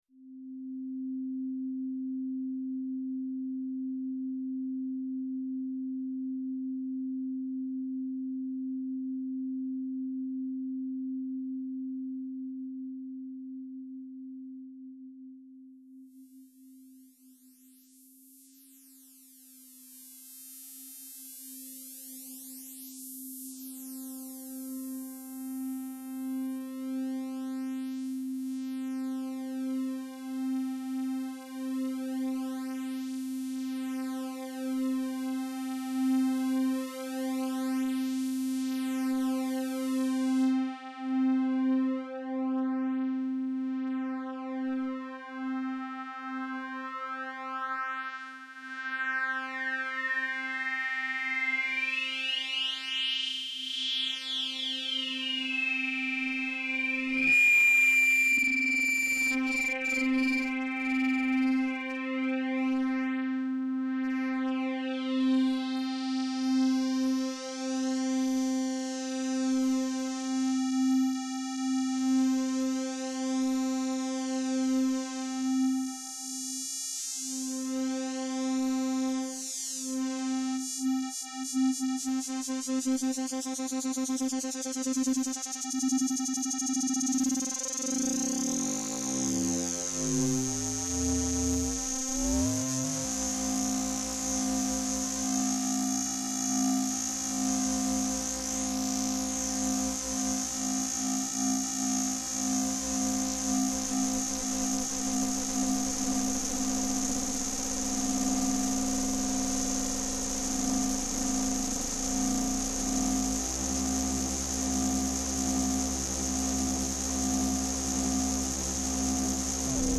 Ich hab' während des Chats ma'n schnell gejammed und aus EINEM sinus 'n klang geformt durch etwas filtern und LFO-manipulation, später habe ich noch 2 weitere sinus-ober- und untertöne beigemengt.
sinus.mp3